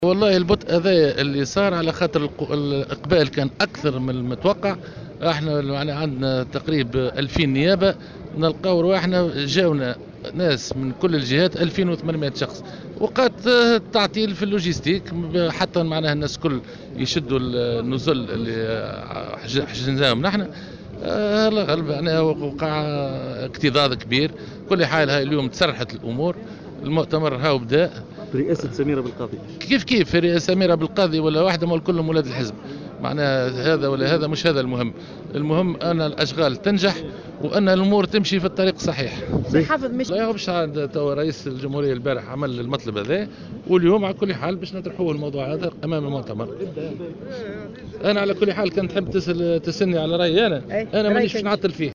وأضاف في تصريح لمراسل "الجوهرة أف أم" أن نحو 2800 مؤتمر يشارك في المؤتمر، معربا عن امله في نجاح أشغاله.